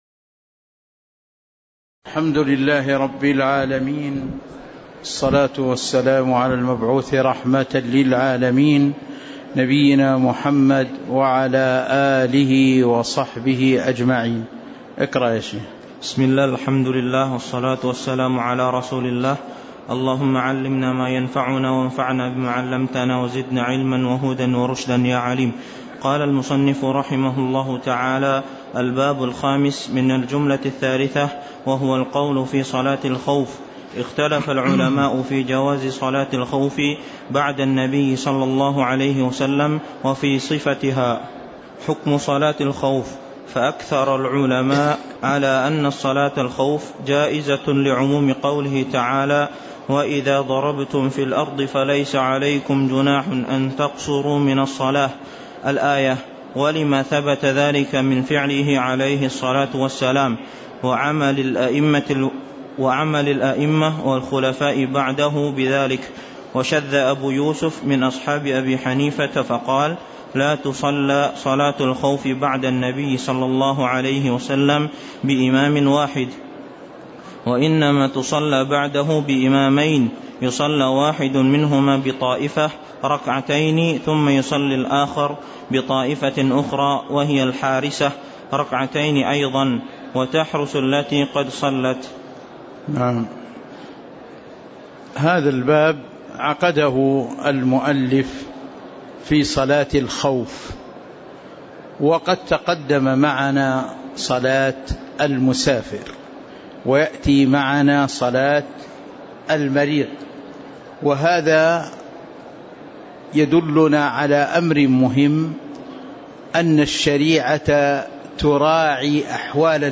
تاريخ النشر ٢١ شوال ١٤٤٣ هـ المكان: المسجد النبوي الشيخ